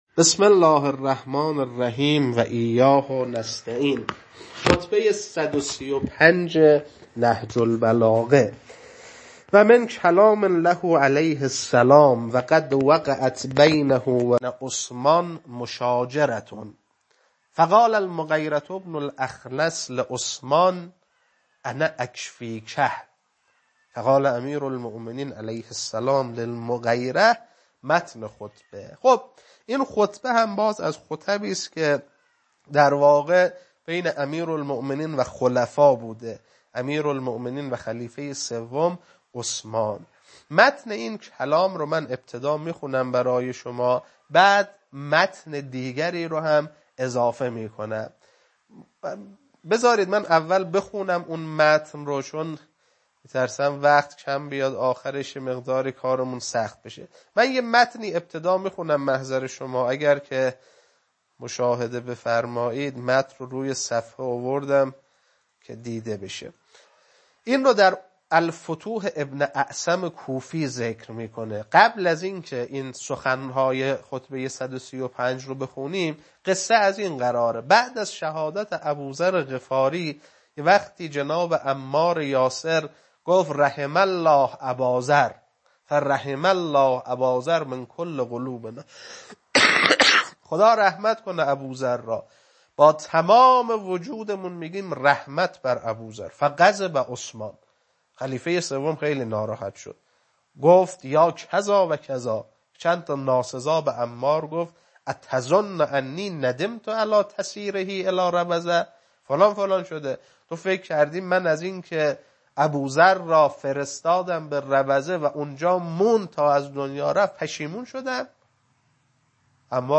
خطبه 135.mp3
خطبه-135.mp3